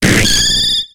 Cri de Yanma dans Pokémon X et Y.